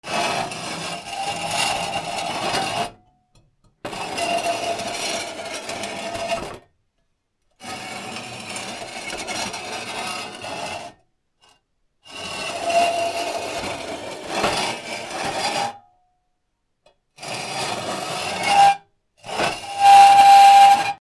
錆びた金属をこすり合わせる
/ M｜他分類 / L01 ｜小道具 / 金属